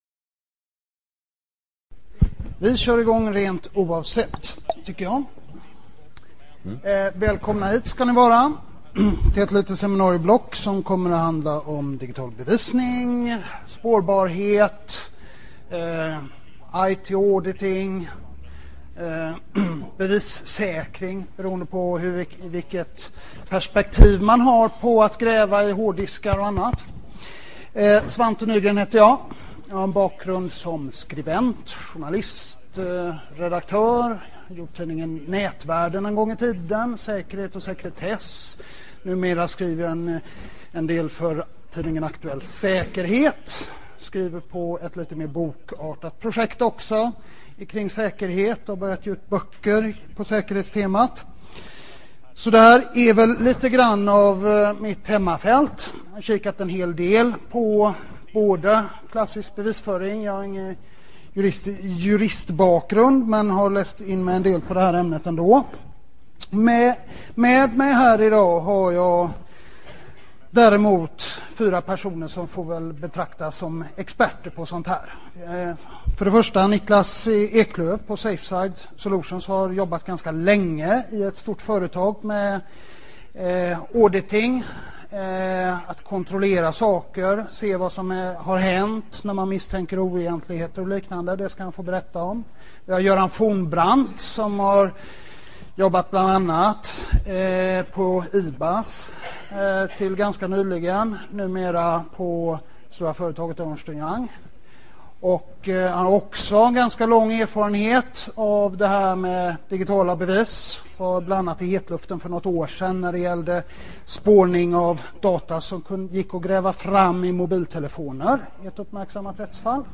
I det h�r seminarieblocket beskriver vi hur s�dan teknisk granskning av datorer g�r till, vilka juridiska ramar som g�ller, vilka fallgropar som man till varje pris m�ste undvika samt hur man v�rderar digitala bevis i en domstol.